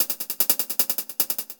Metalico 02.wav